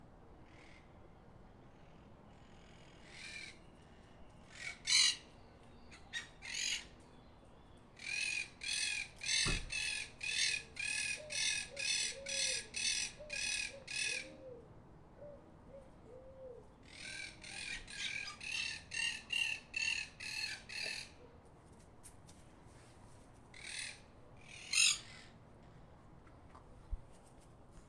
Cotorra (Myiopsitta monachus)
Nombre en inglés: Monk Parakeet
Fase de la vida: Adulto
Condición: Silvestre
Certeza: Observada, Vocalización Grabada